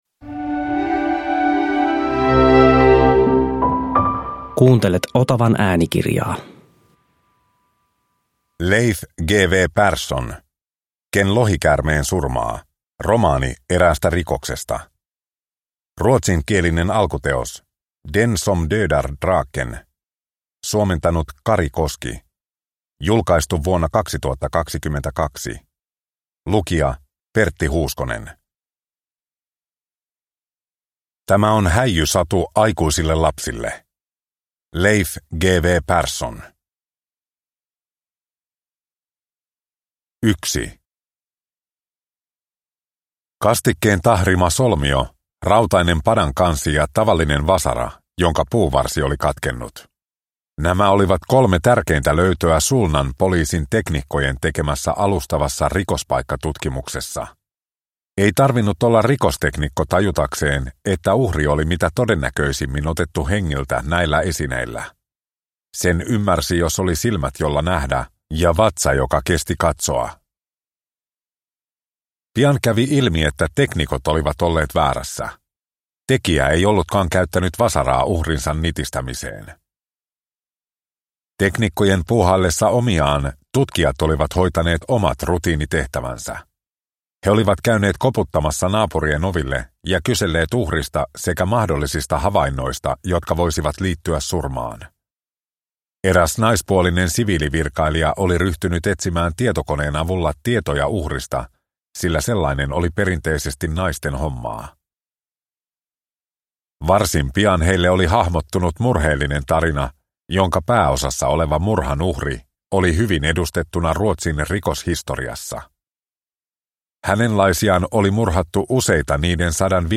Ken lohikäärmeen surmaa – Ljudbok – Laddas ner